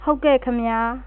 22_Yes (Male)
22_Yes-Male.wav